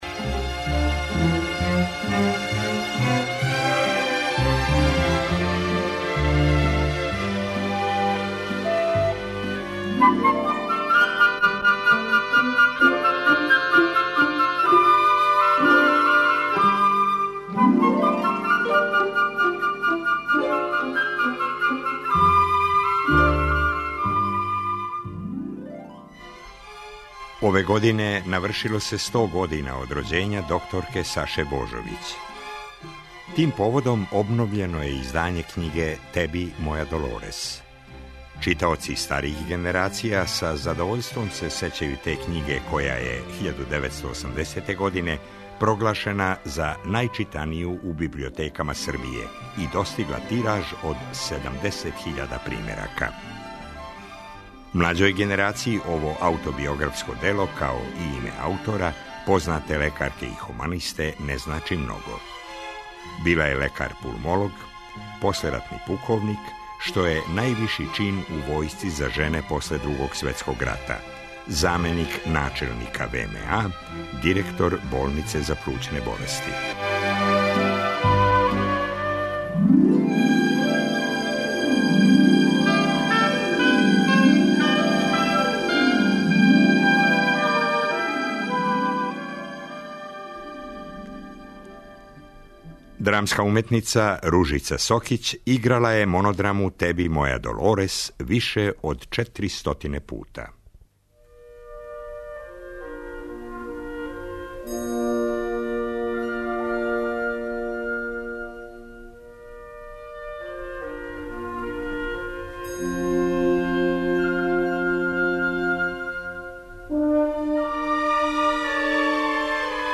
Докторка Божовић је била гост ове емисије 1989. године. Емитујемо је поново као и одломке из монодраме "Теби, моја Долорес", у интерпретацији глумице Ружице Сокић.